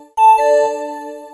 beep2.wav